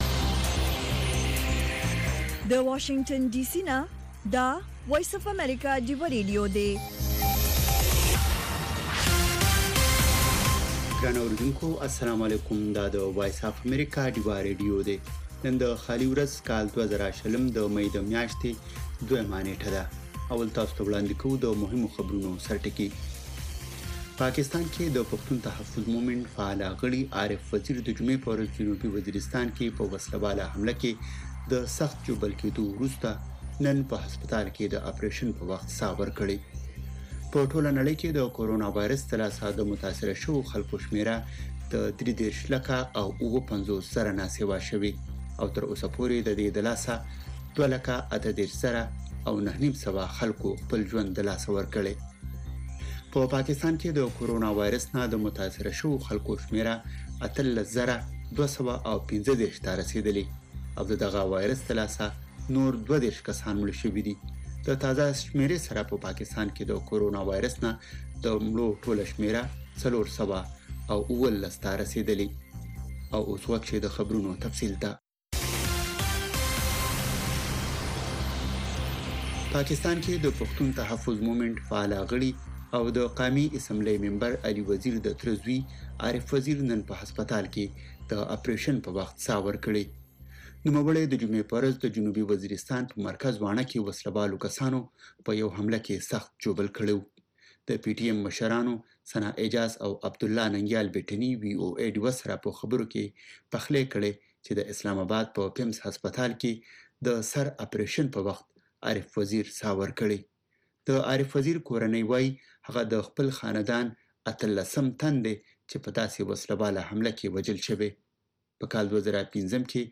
خبرونه
د وی او اې ډيوه راډيو خبرونه چالان کړئ اؤ د ورځې د مهمو تازه خبرونو سرليکونه واورئ.